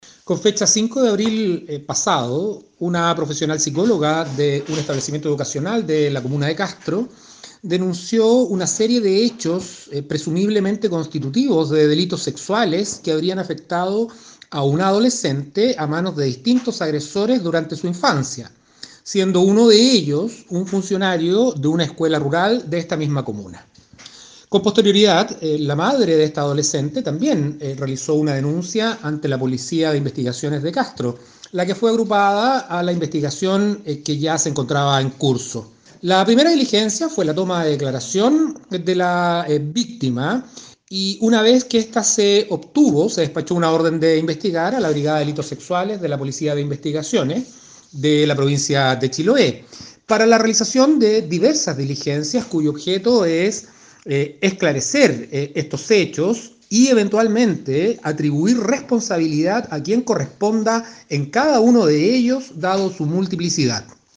Escuchemos lo señalado respecto de este hecho grave, por parte del fiscal de la comuna de Castro, Enrique Canales.
20-FISCAL-ENRIQUE-CANALES-INVESTIGACION-DELITO-SEXUAL.mp3